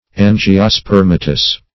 Search Result for " angiospermatous" : The Collaborative International Dictionary of English v.0.48: Angiospermatous \An`gi*o*sper"ma*tous\ ([a^]n`j[i^]*[-o]*sp[~e]r"m[.a]*t[u^]s), a. (Bot.)
angiospermatous.mp3